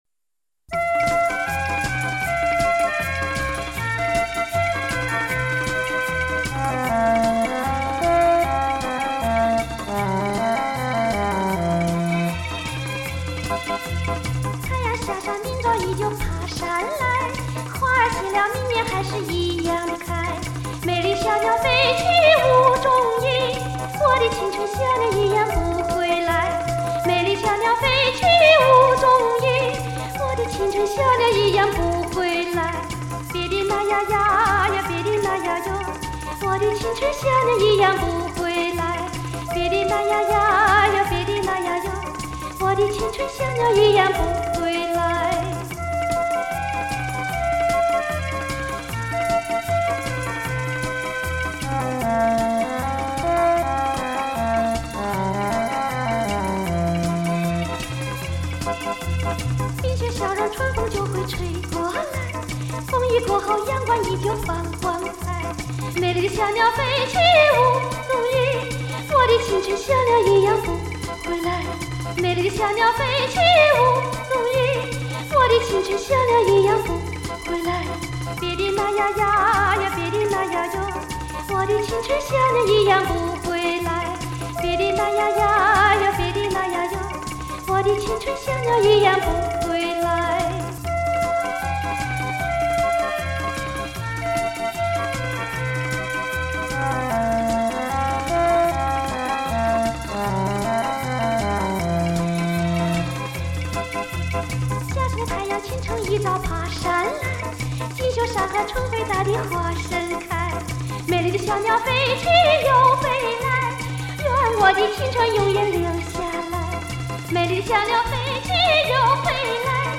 新疆民歌